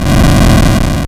M1_Fire.wav